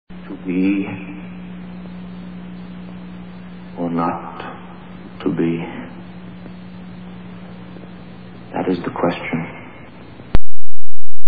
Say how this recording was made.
The opening scene.